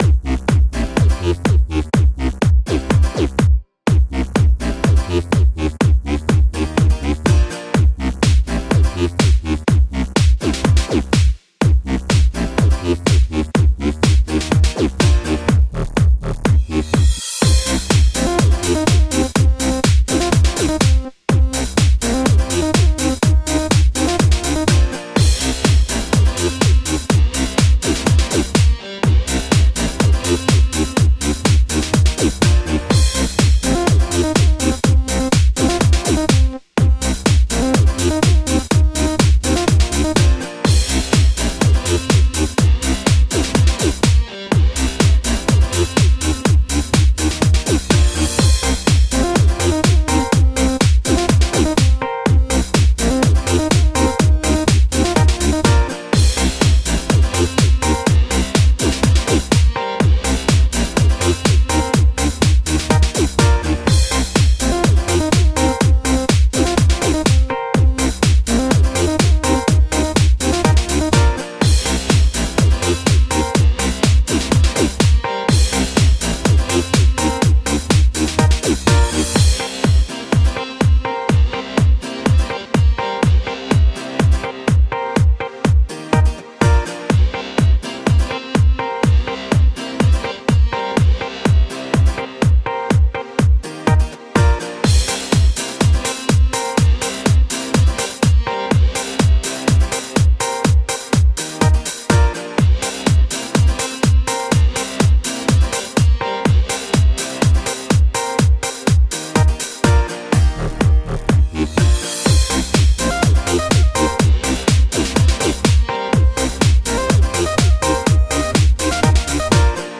Tagged as: Electronica, Pop, Chillout, Happy Hour